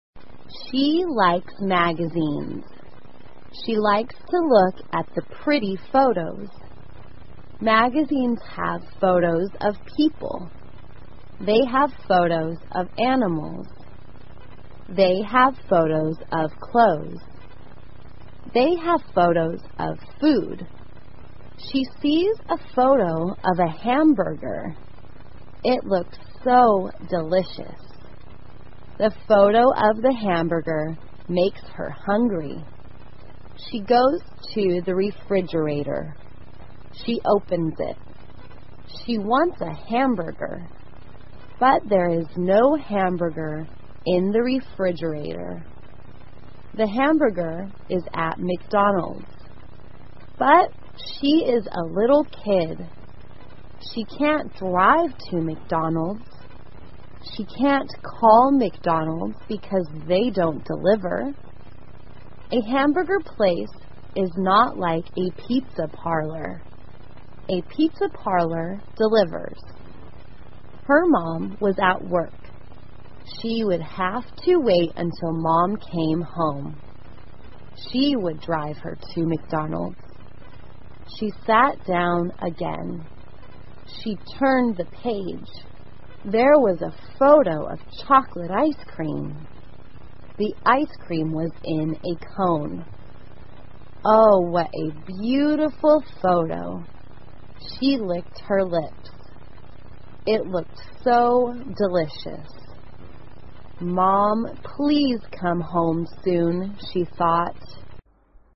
慢速英语短文听力 她很饿 听力文件下载—在线英语听力室